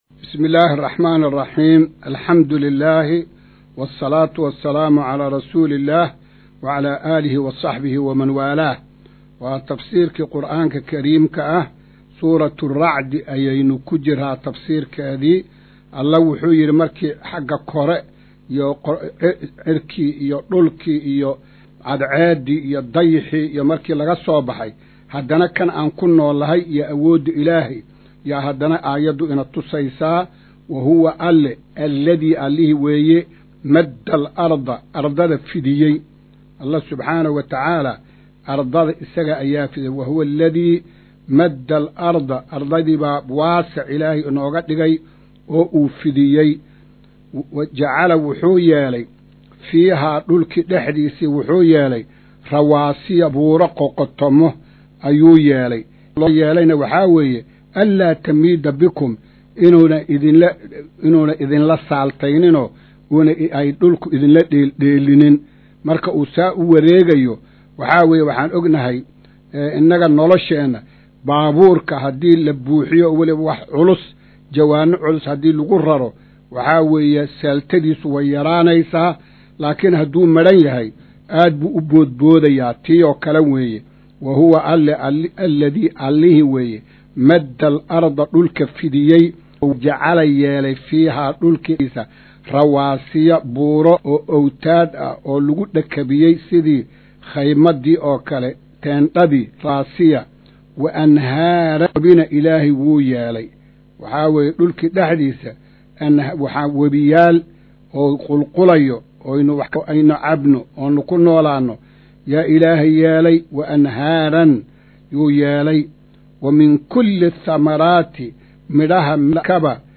Maqal:- Casharka Tafsiirka Qur’aanka Idaacadda Himilo “Darsiga 123aad”
Casharka-123aad-ee-Tafsiirka.mp3